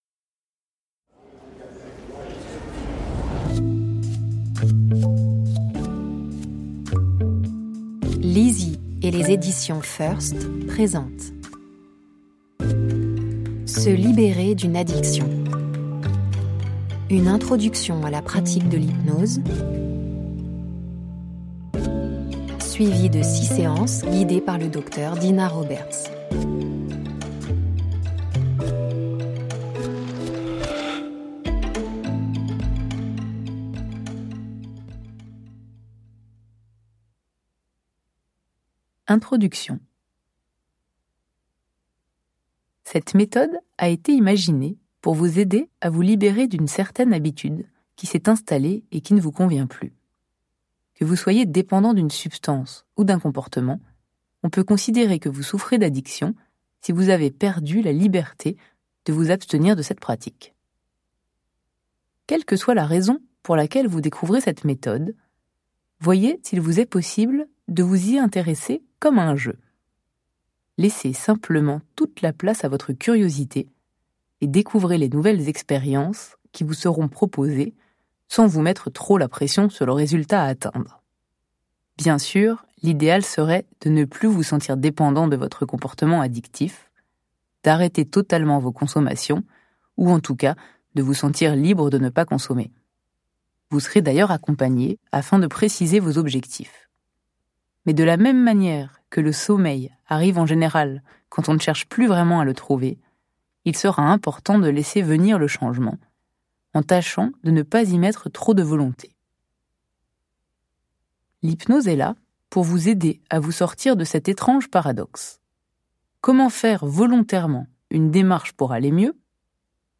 Installez-vous confortablement, laissez-vous guider par la voix d'une experte et prolongez votre écoute avec son livre compagnon !